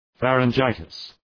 Προφορά
{,færın’dʒaıtıs}